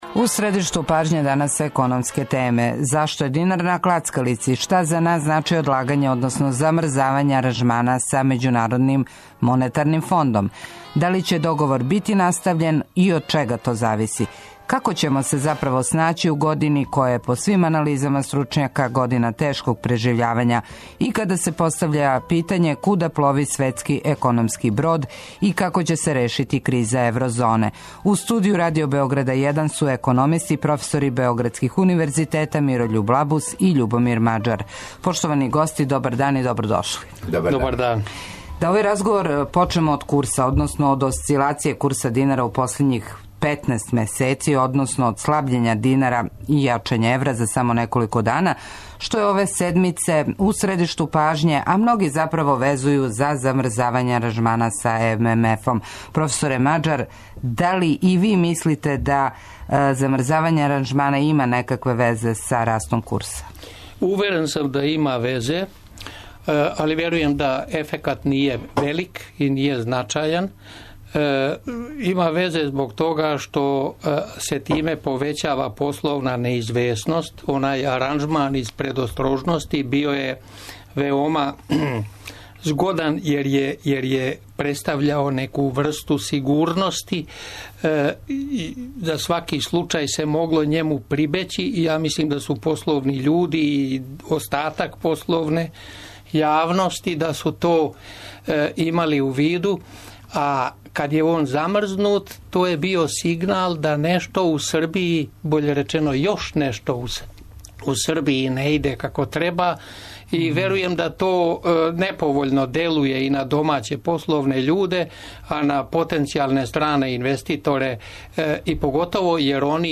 На ова и друга питања одговарају гости емисије У средишту пажње - економисти, професори Мирољуб Лабус и Љубомир Маџар.